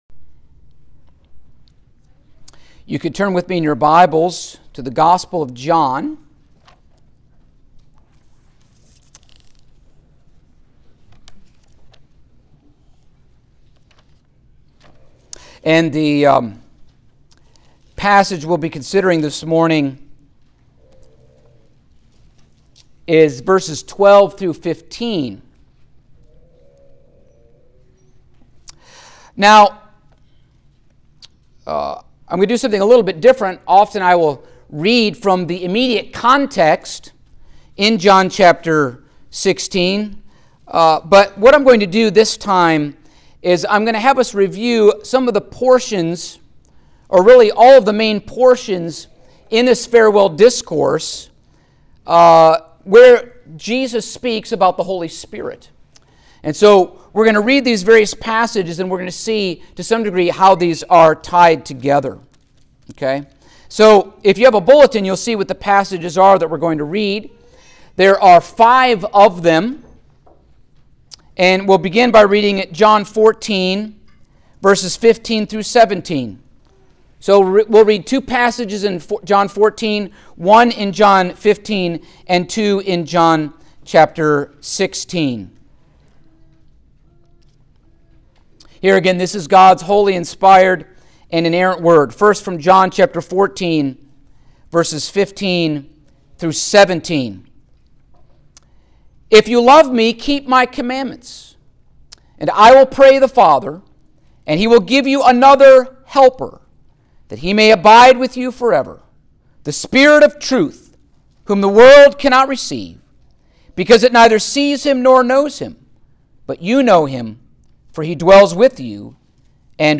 Passage: John 16:12-15 Service Type: Sunday Morning